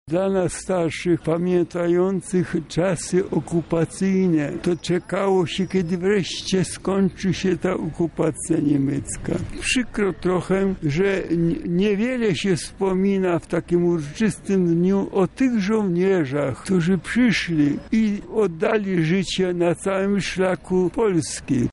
Mieszkańcy Lublina uczcili pamięć poległych w walce z okupantem.